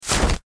umbrella_open_03.ogg